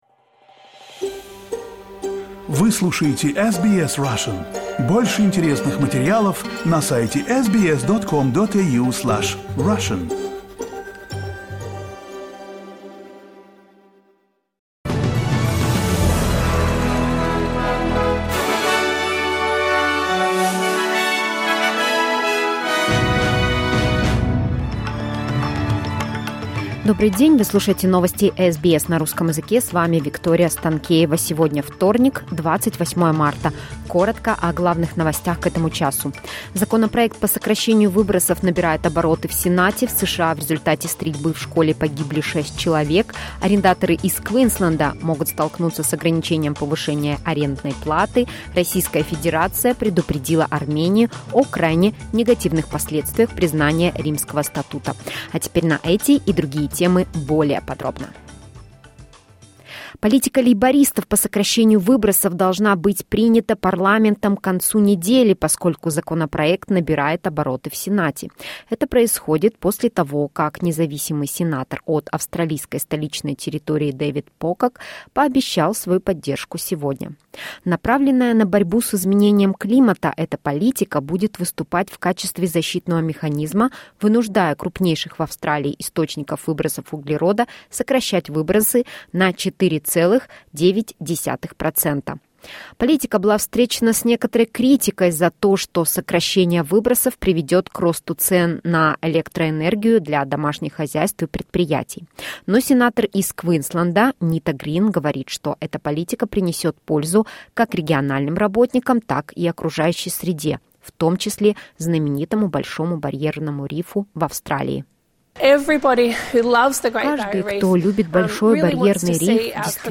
SBS news in Russian — 28.03.2023